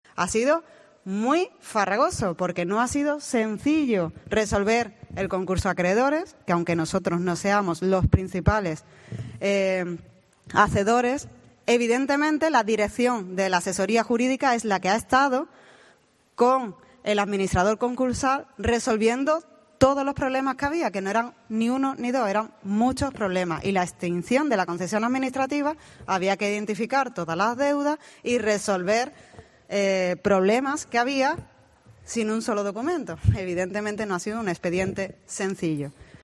Escucha aquí a los concejales Raúl Ruiz-Berdejo (Adelante Jerez), jaime Álvarez (PP) y Laura Álvarez (PSOE)